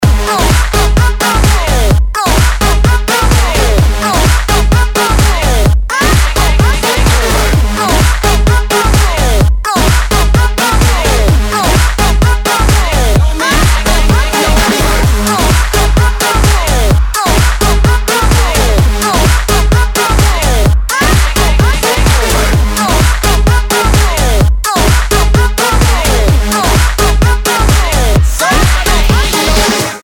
• Качество: 320, Stereo
Клубный рингтончик с крутой музыкой от неизвестных Ди-Джеев